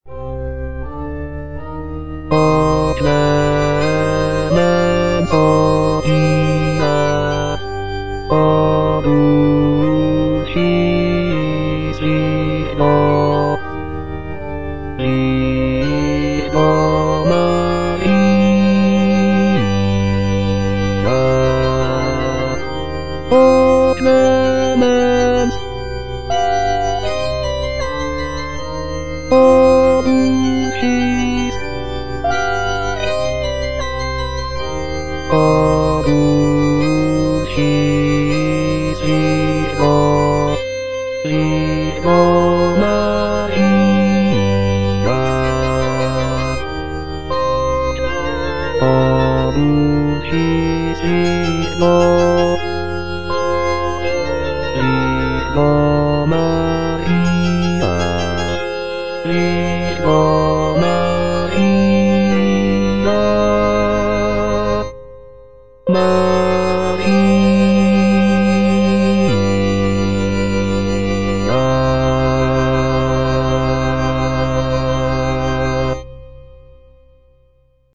G.B. PERGOLESI - SALVE REGINA IN C MINOR O clemens, o pia - Bass (Emphasised voice and other voices) Ads stop: auto-stop